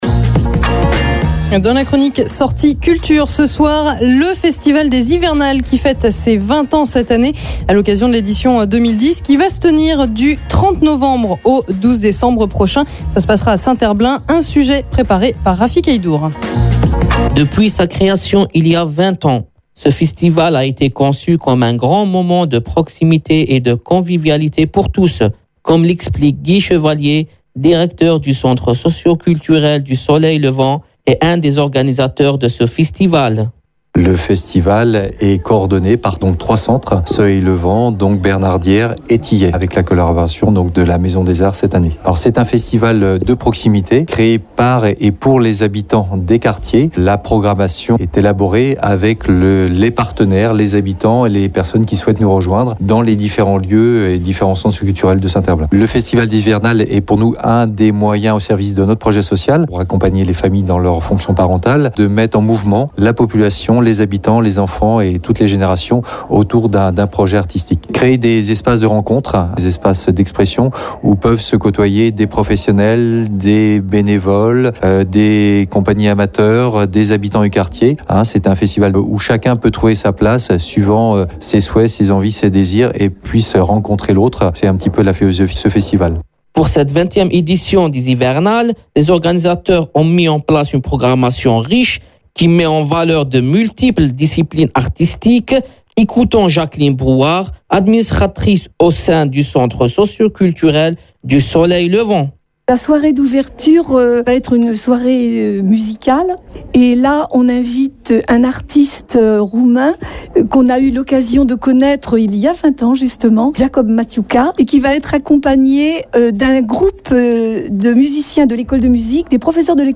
reportage_hiver.mp3